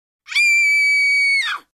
Звуки женского крика
Писклявый крик